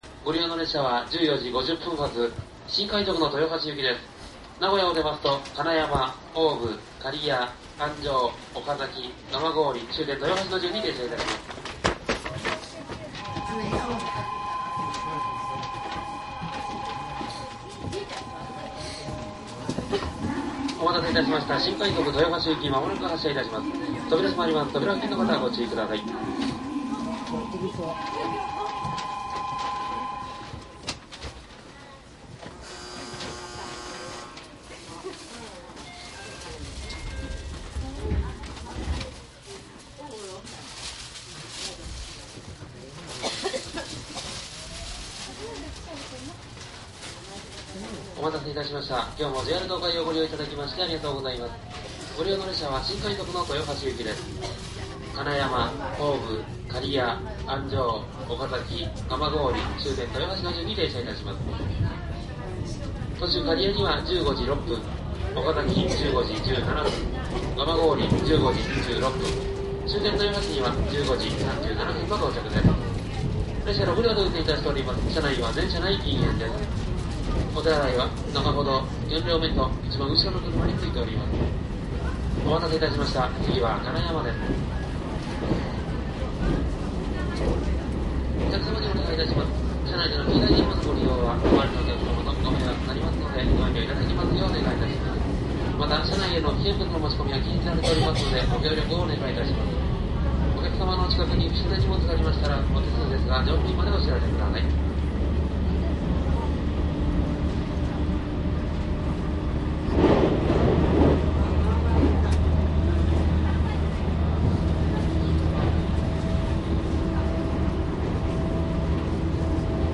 東海 313系 新快速走行音 ＣＤ♪
東海道線上り 313系 新快速 録音 ＣＤです。
■【新快速】大垣→名古屋 クモハ313－7
マスター音源はデジタル44.1kHz16ビット（マイクＥＣＭ959）で、これを編集ソフトでＣＤに焼いたものです。